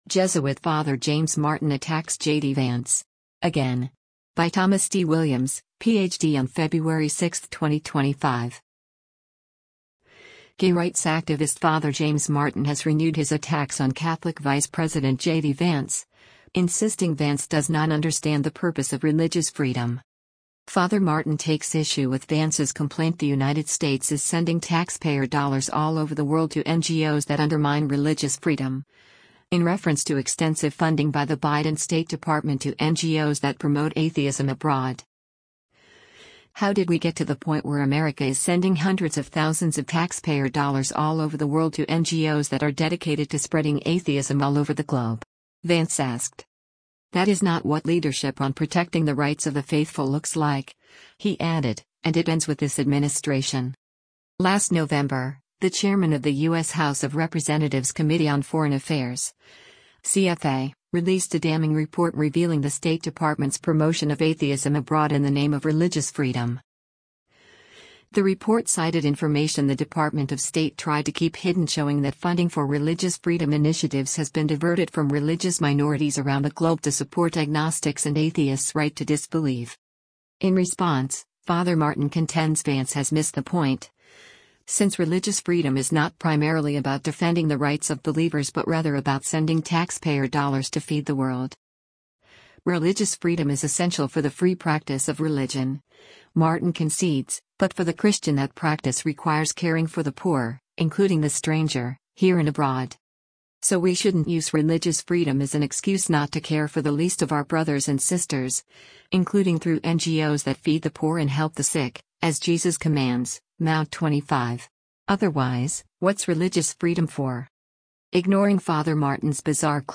US priest Father James Martin speaking at the world meeting of families in Dublin, on how